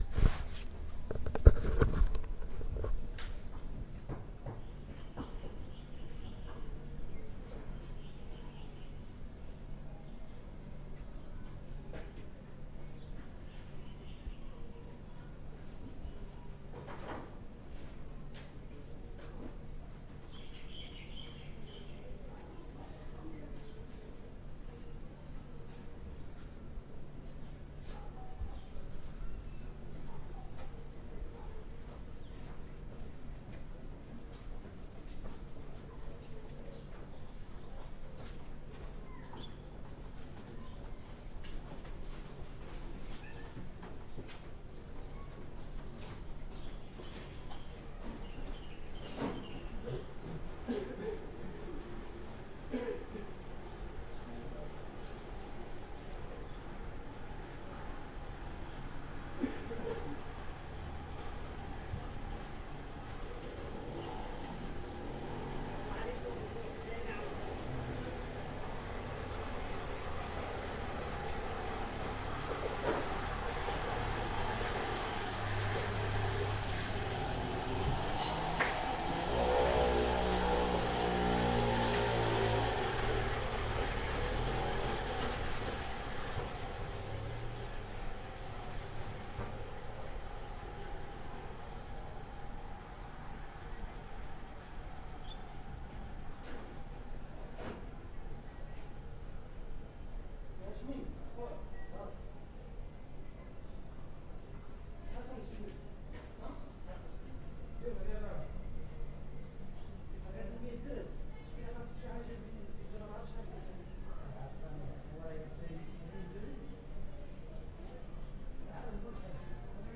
描述：当城市醒来时，一个清晨走过马拉喀什的后街。交通，人群，孩子，摩托车。
Tag: FIEL 非洲 马拉喀什 旅游 现场记录